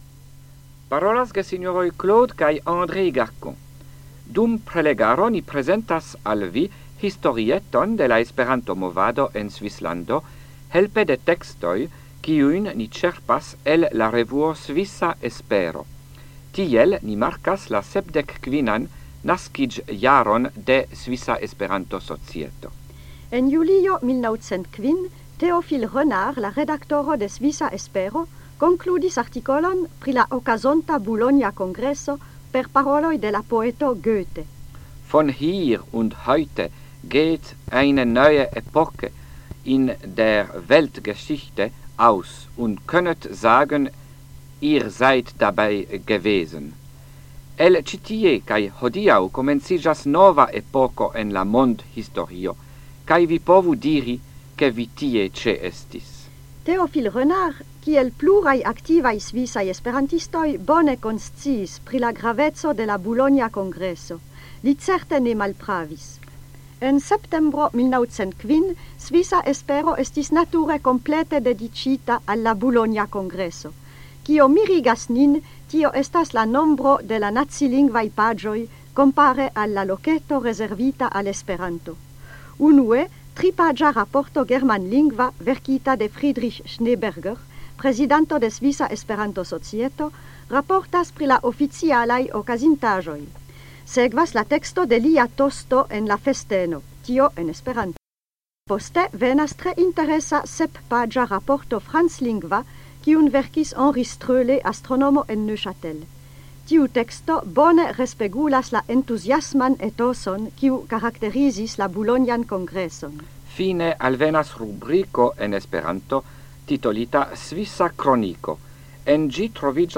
Radioprelegoj en la jaro 1978